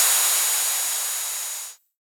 RDM_TapeB_SY1-OpHat.wav